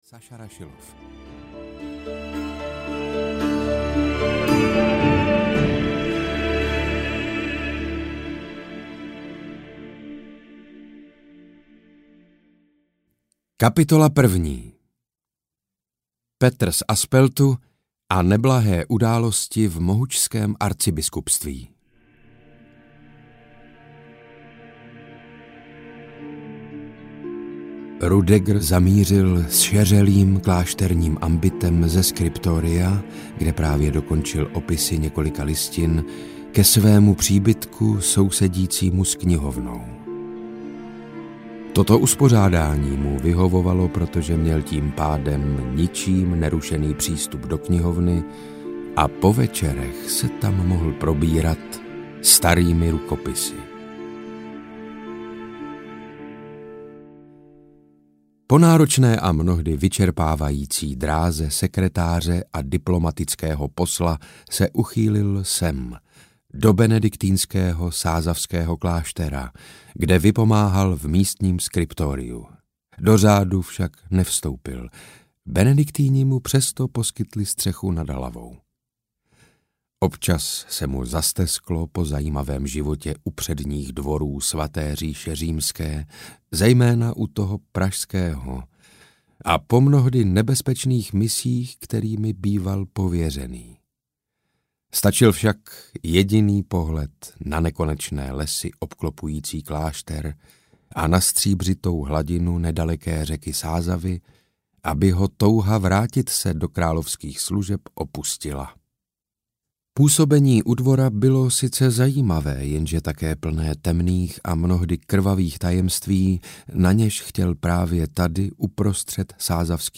Ukázka z knihy
• InterpretSaša Rašilov